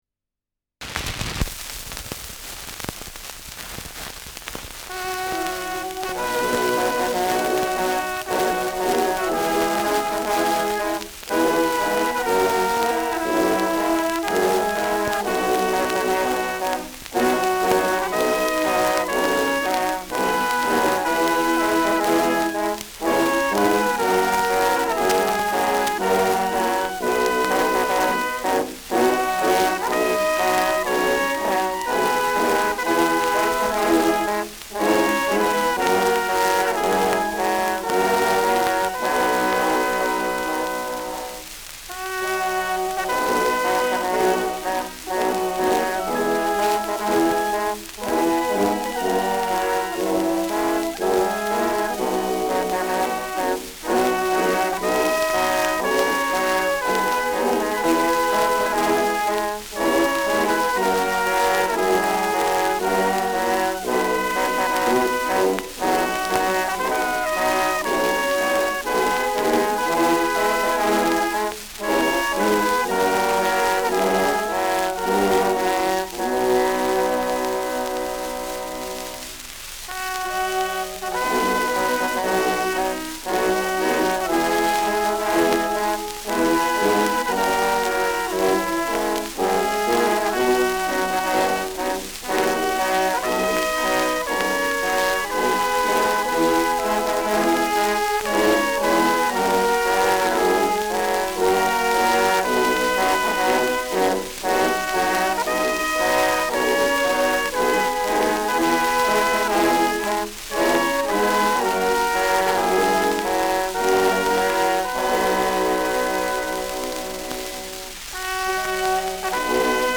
Schellackplatte
Tonrille: Kratzer Durchgehend Leicht : Kratzer 2 / 4 / 9 Uhr Stärker : Berieb 2 Uhr Stärker
Stärkeres Grundrauschen : Vereinzelt leichtes Knacken
[Ansbach] (Aufnahmeort)